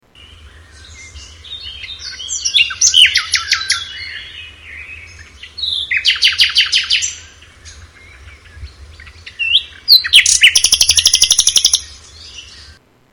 nightengale
Tags: soothing sounds great traveling companions daily inspiration nature at its best natual inner healing for your spirit